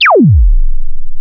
55aj-zap17-e2.aif